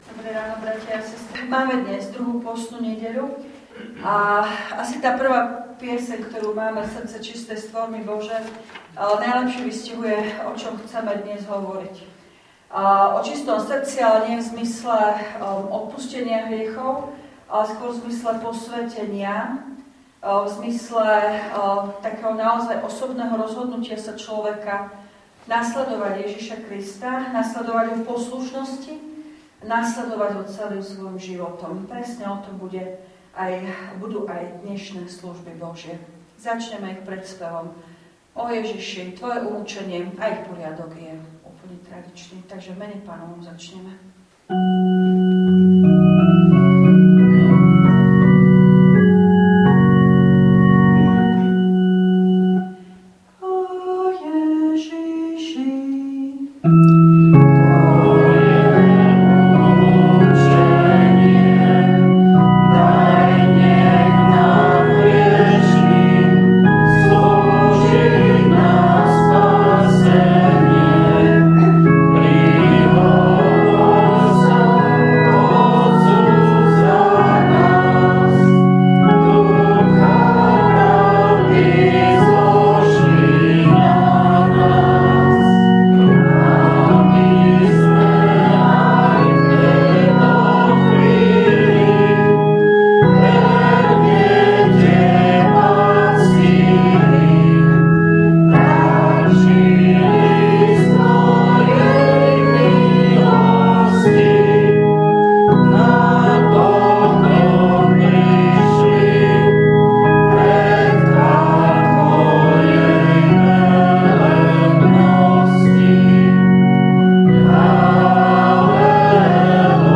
Služby Božie – 2. nedeľa pôstna
V nasledovnom článku si môžete vypočuť zvukový záznam zo služieb Božích – 2. nedeľa pôstna.